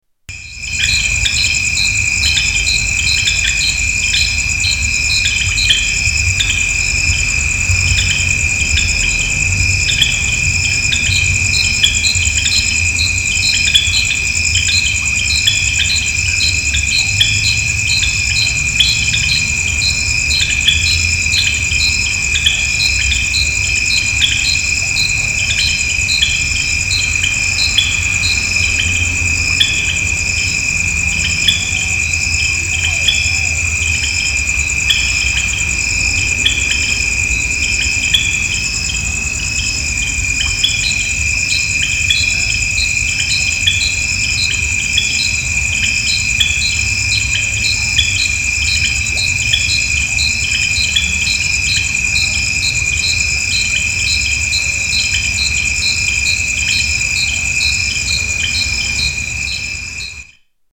Frogs by the bridge at Bel